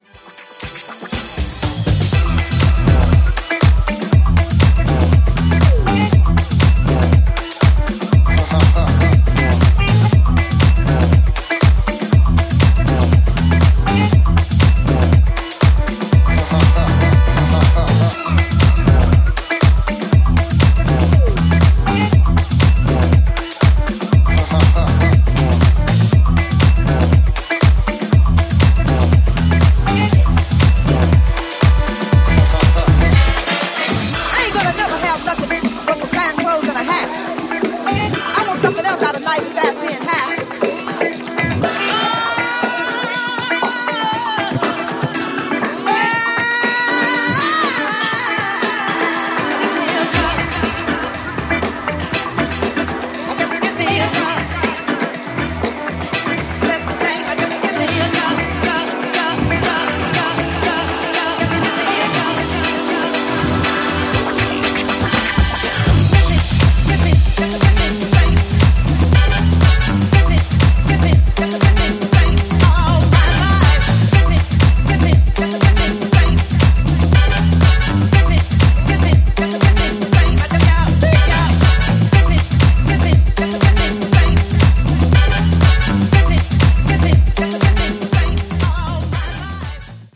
ニューディスコ、ディスコ、ファンク、といったジャンルをファンキーなソウルフルハウスで仕上げたスペシャルなトラック全6曲！
ジャンル(スタイル) NU DISCO / DISCO / HOUSE